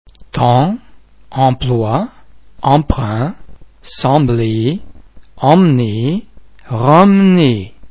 en em an am vowel-base similar to ong in (pong)
·[em]
en_temps.mp3